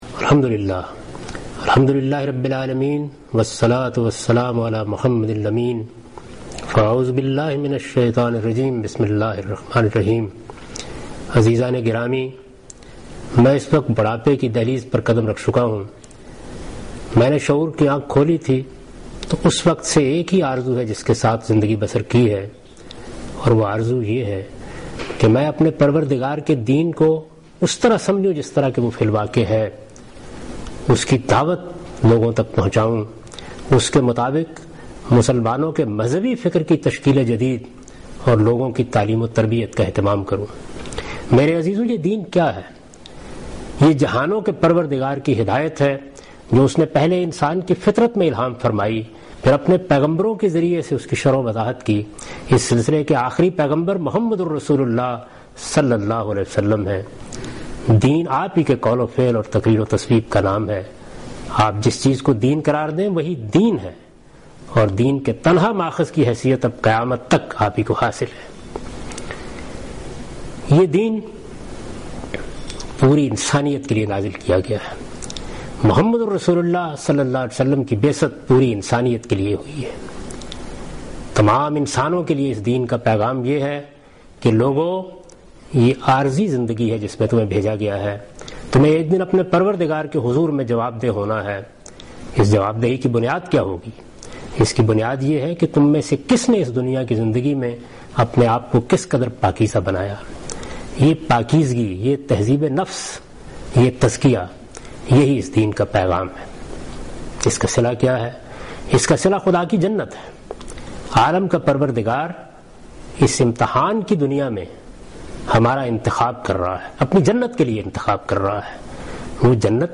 اس پروگرام میں جاوید احمد غامدی اسلام کی دعوت دے رہے ہیں